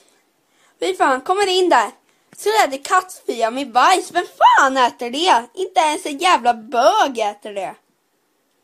Sound Buttons: Sound Buttons View : Kattspya Med Bajs
kattspya-1.mp3